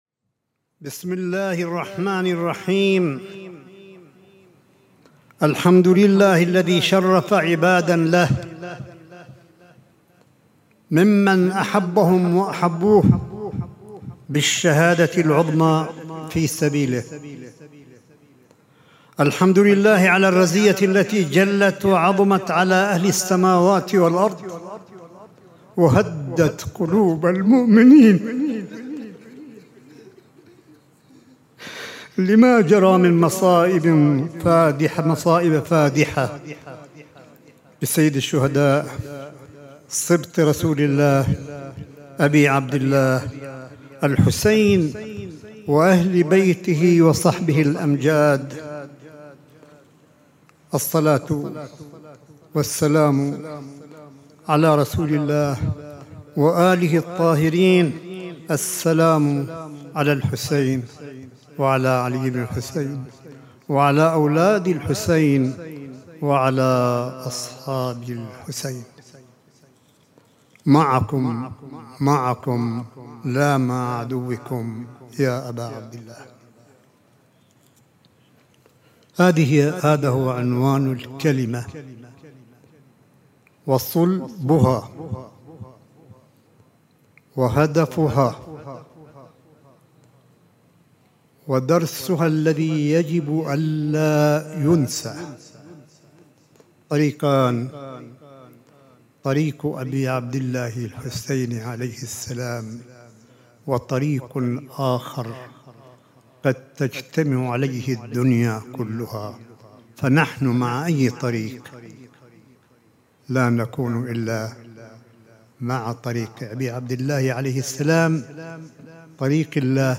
صوت : خطاب عاشوراء لآية الله قاسم ليلة العاشر من محرم 1442 هـ – قم المقدسة
ملف صوتي لخطاب عاشوراء لسماحة آية الله الشيخ عيسى أحمد قاسم، ليلة العاشر من محرم 1442 هـ – الحسينية البحرانية في مدينة قم المقدسة 29 اغسطس 2020 م